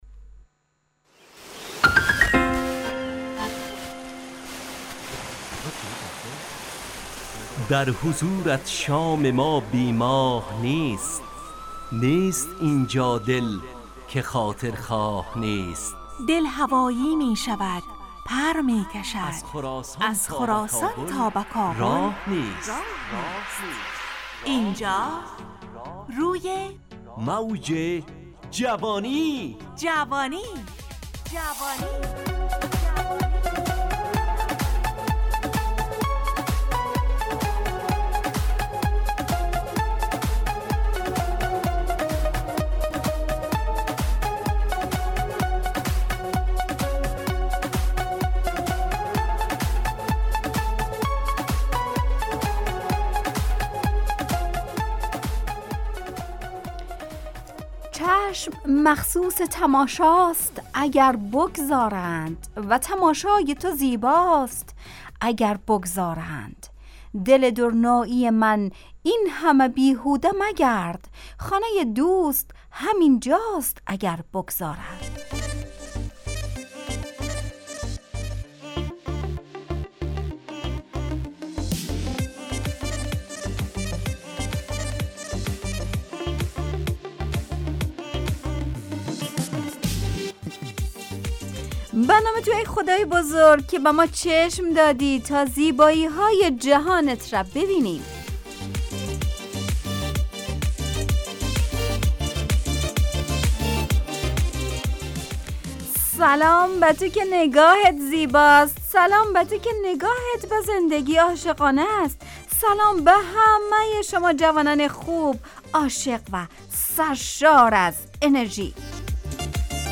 روی موج جوانی، برنامه شادو عصرانه رادیودری. از شنبه تا پنجشنبه ازساعت 17 الی 17:55 طرح موضوعات روز، وآگاهی دهی برای جوانان، و.....بخشهای روزانه جوان پسند....
همراه با ترانه و موسیقی .